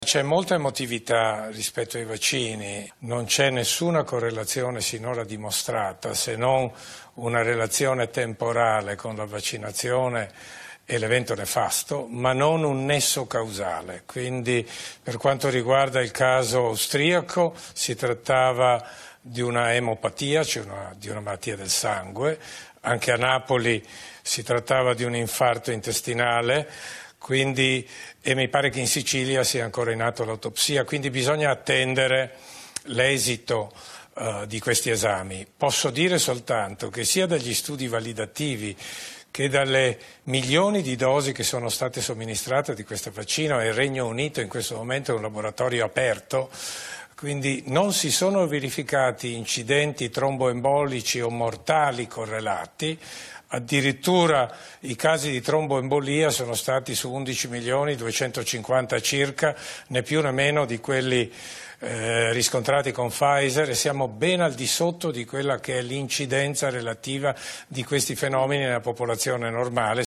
Fino ad ora non c’è nessuna relazione diretta e nessun rischio per il vaccino AstraZeneca” ha detto oggi il presidente dell’Agenzia italiana del Farmaco, l’Aifa, Giorgio Palù, intervistato alla trasmissione Mezz’ora in più: